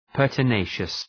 Shkrimi fonetik{,pɜ:rtə’neıʃəs}